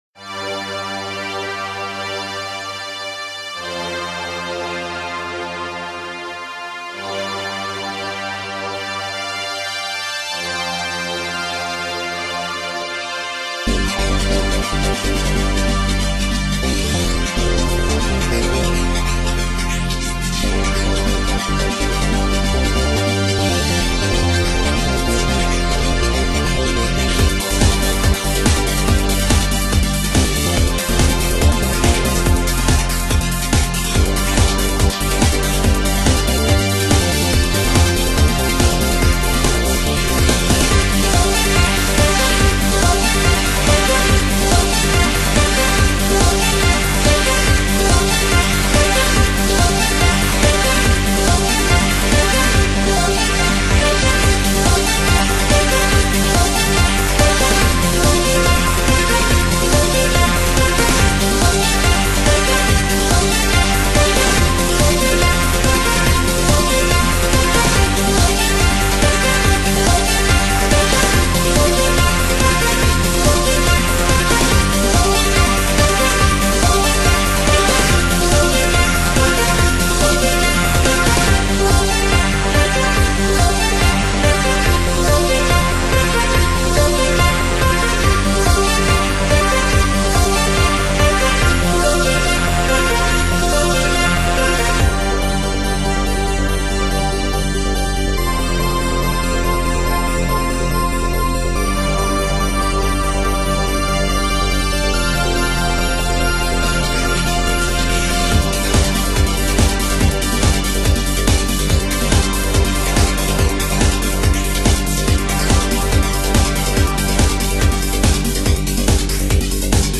Subject description: Trance alert!
but its too repetitive and doesnt really kick in at all... and becomes abit boring